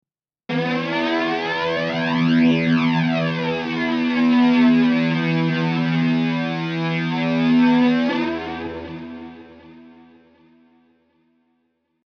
フランジャーというのはエフェクターの一種だわ。
シュワシュワ炭酸おしゃれだよ、りんちゃん！
普通はジェット機の音の様だと言うけれど。ジェットサウンドと呼ばれる事もあるわ。